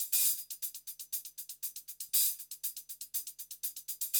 HIHAT LO13.wav